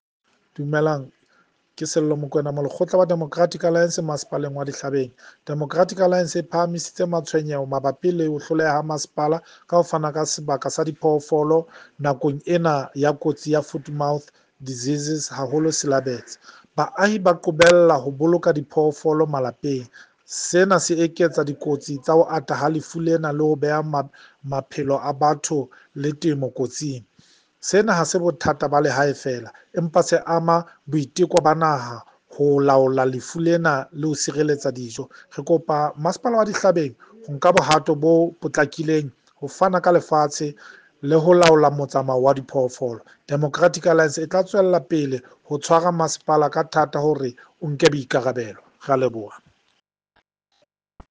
Sesotho soundbites by Cllr Sello Makoena and